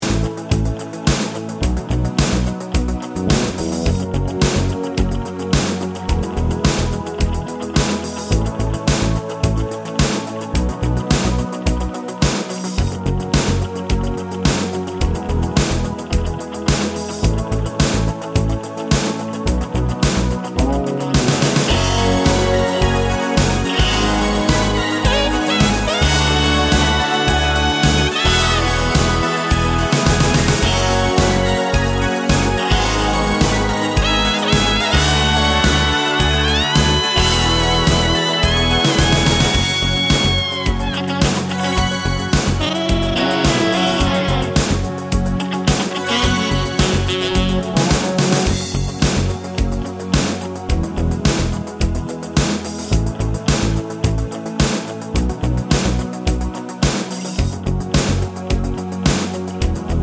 Minus Saxophone Soundtracks 4:52 Buy £1.50